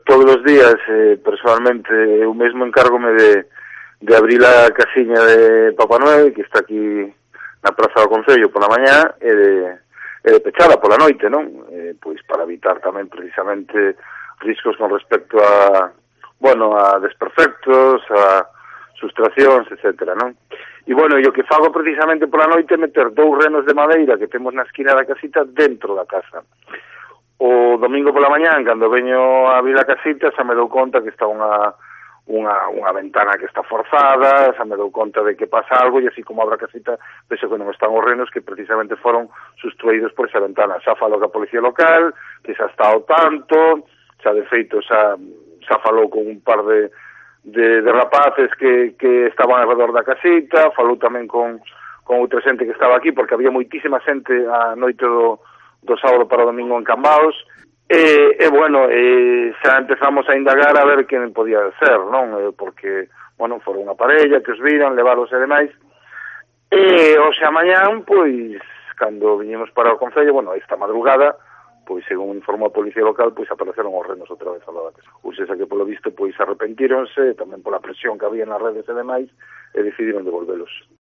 El concejal de Seguridad Ciudadana de Cambados, Tino Cordal, explica el robo de los renos navideños